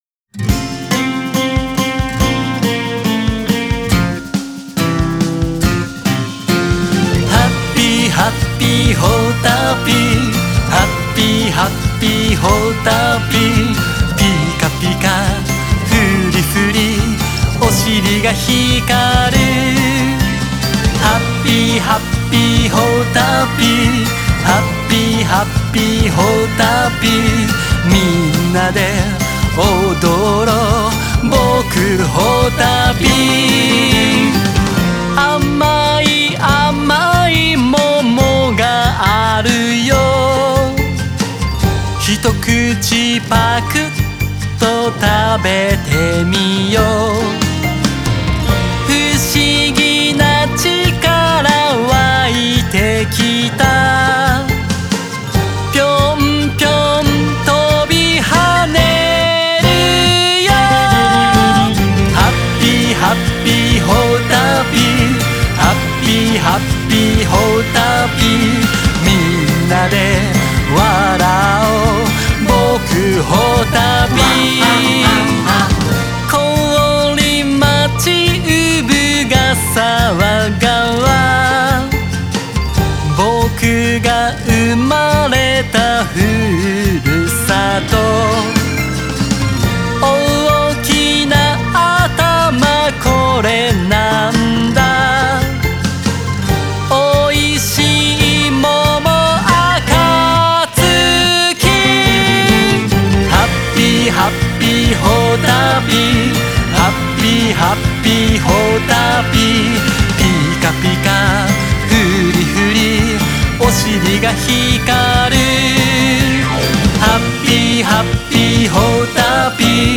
口ずさみやすい曲調で、元気いっぱい、楽しく踊っています。
作詞・作曲・歌